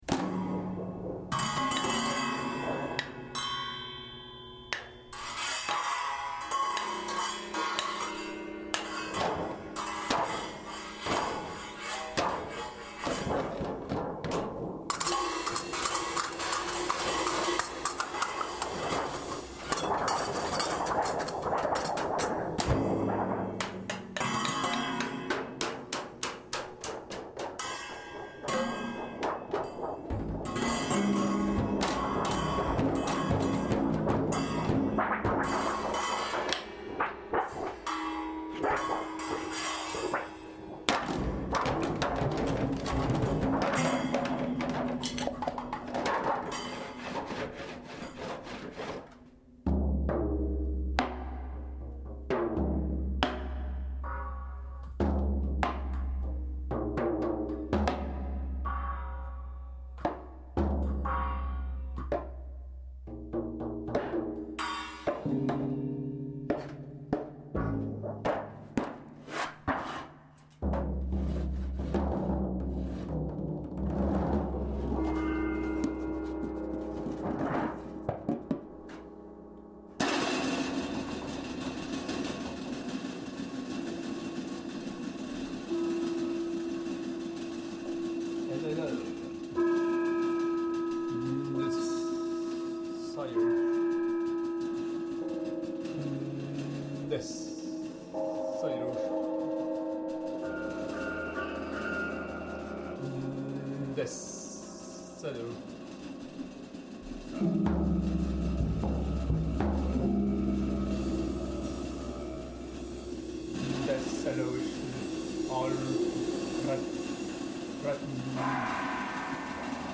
Duo de Sons
textures sonores
percussions